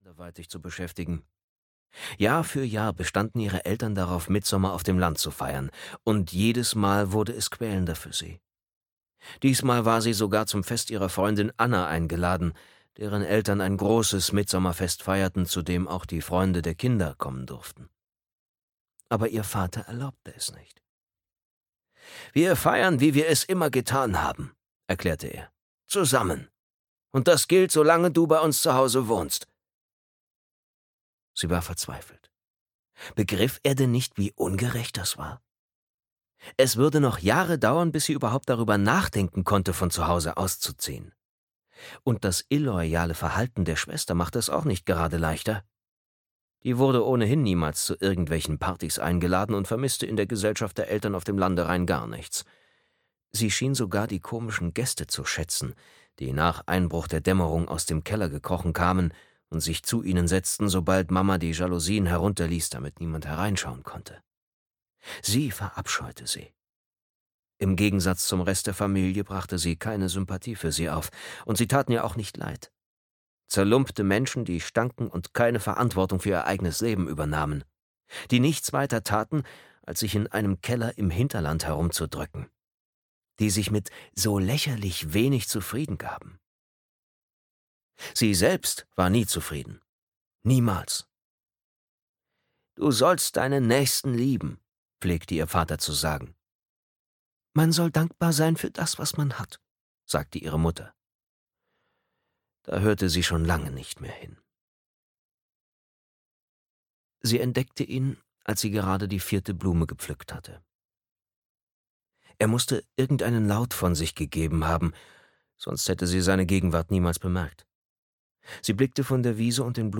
Hörbuch Tausendschön von Kristina Ohlsson.
Ukázka z knihy